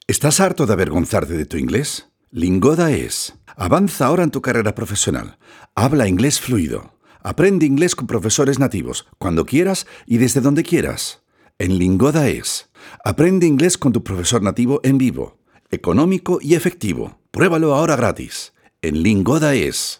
Zwischen Spanien und Deutschland zweisprachig hin und her pendelnd lernte er 2 Sprachen akzentfrei zu sprechen.
Sprechprobe: Werbung (Muttersprache):